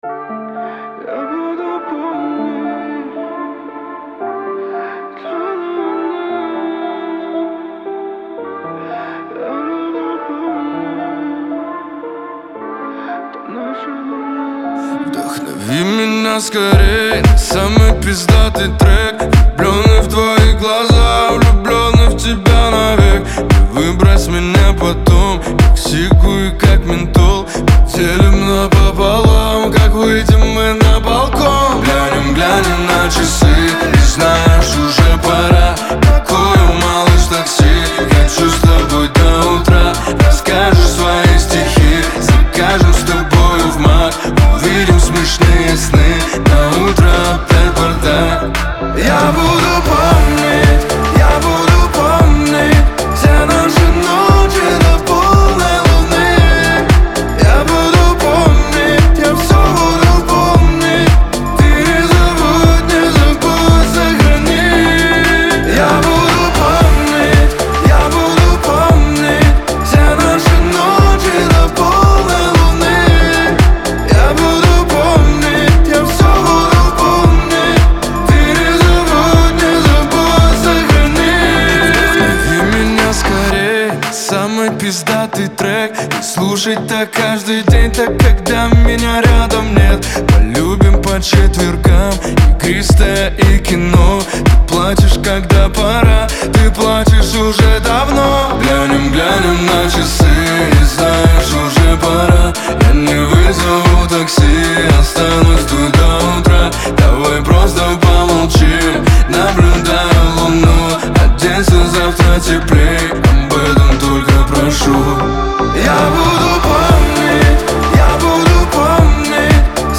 относится к жанру поп и обладает меланхоличным настроением